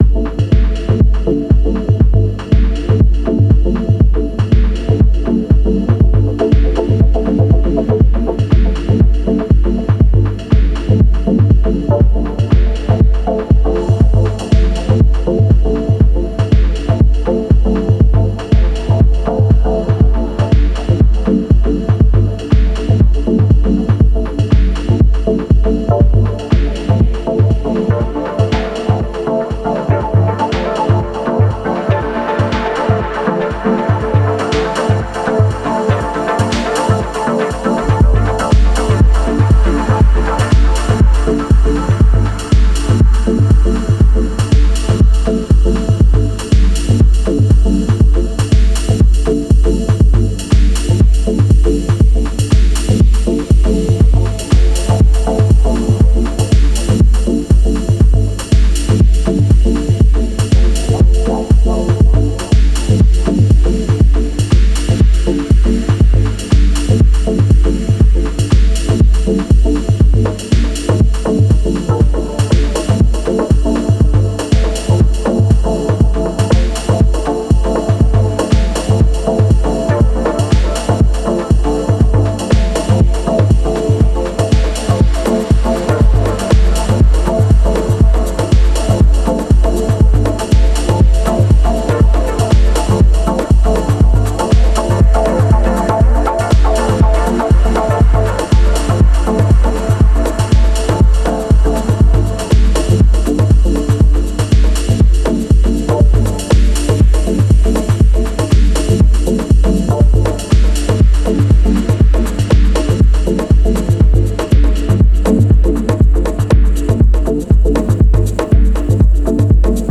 Best synth for dub chords (dub techno)
It’s a different vibe, more dusty, than the Typhon.
But really nice for a bit more groovy dub.
Here’s a livetake I recorded this morning.
Digitone via Strymon Deco for chords.
Deluge for everything else.